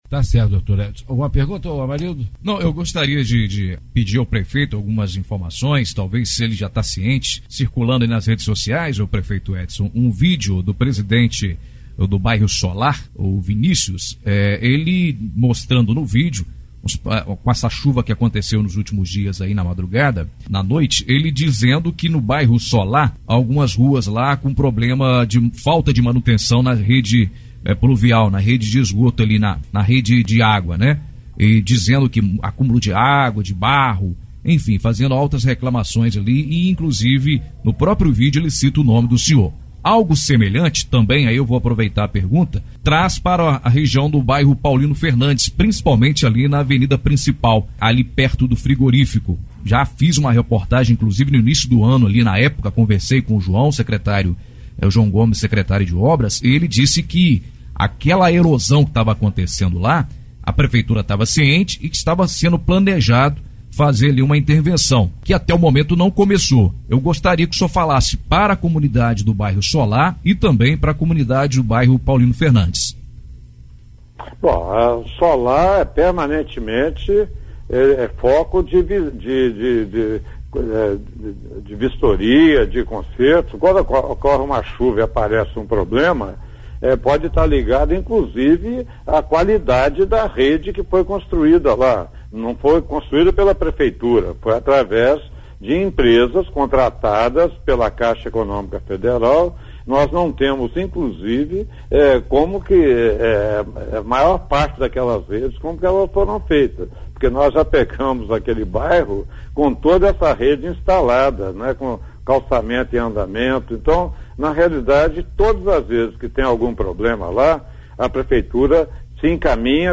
Em entrevista ao Programa Fato do Dia, na Rádio Ubaense FM – 104,1 – o prefeito de Ubá, Edson Teixeira Filho, respondeu aos jornalistas